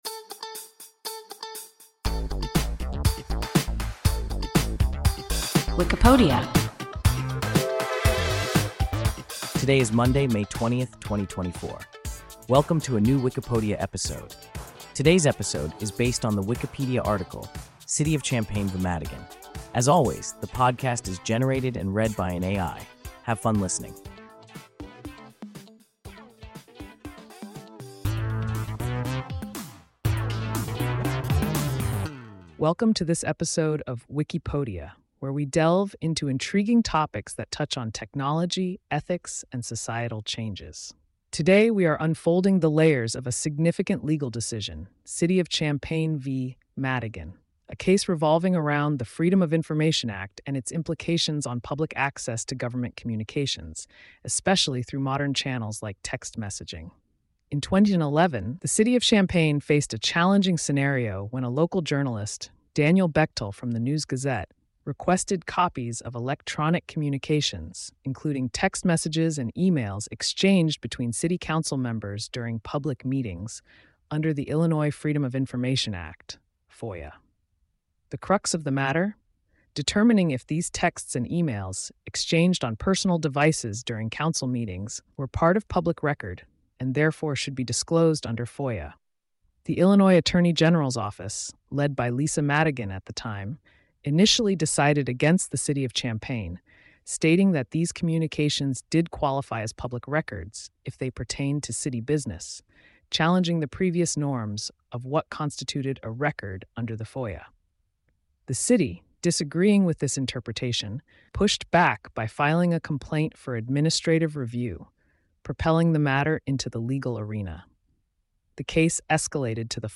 City of Champaign v. Madigan – WIKIPODIA – ein KI Podcast